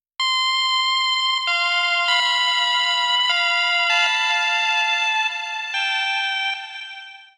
描述：简单的结晶性合成器旋律。
Tag: 130 bpm Chill Out Loops Synth Loops 1.24 MB wav Key : Unknown